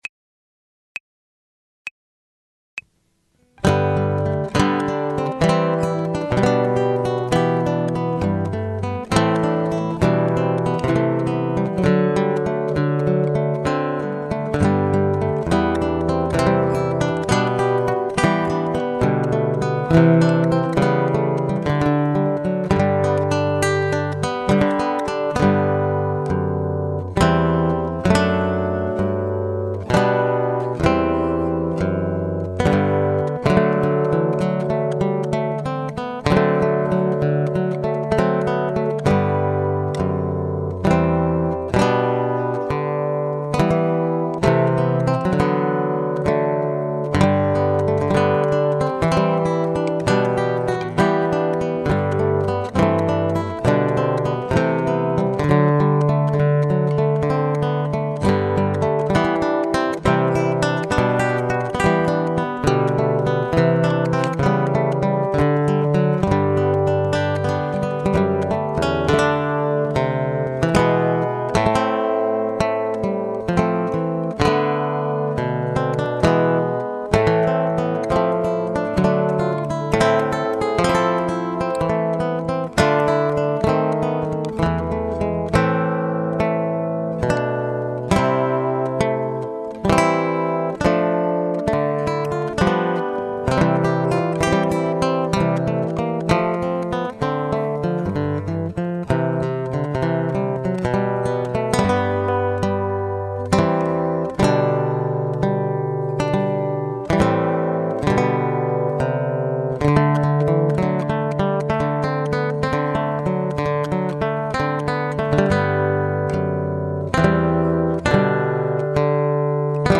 guitar ensemble arrangements